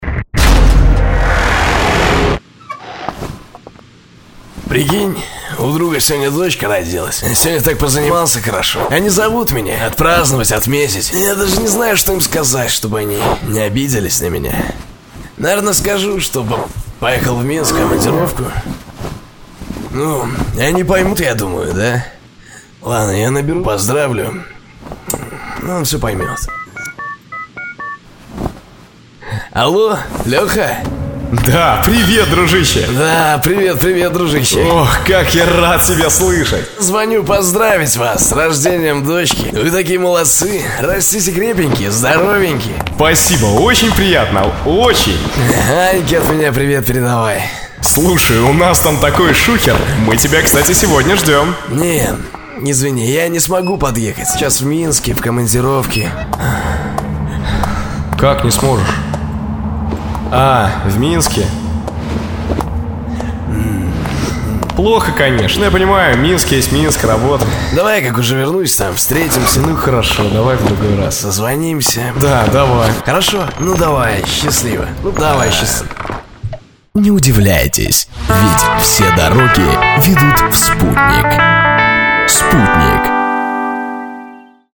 Смонтированный звуковой ряд,имитирующий реальные условия хорошей записи. Озвучка - одним и тем же диктором